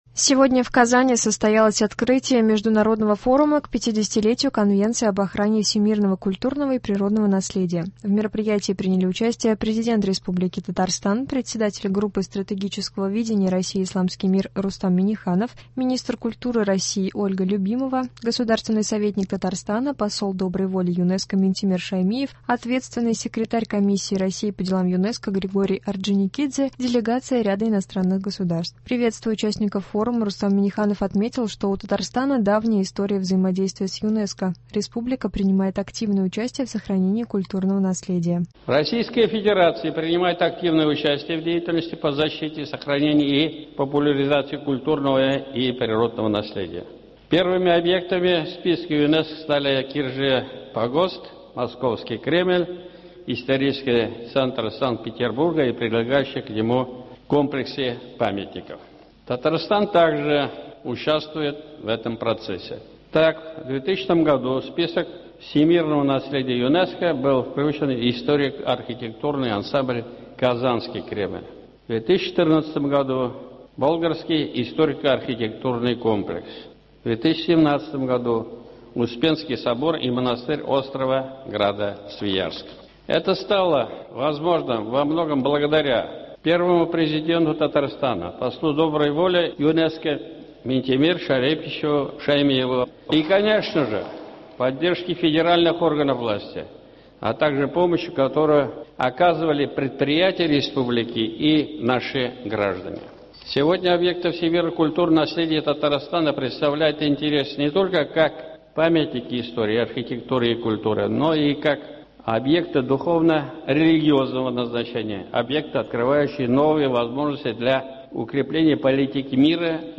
Новости (5.12.22)